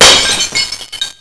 Here's a little "wav" file of a glass-breaking sound that you can download (useful for your "exiting Windows" sound):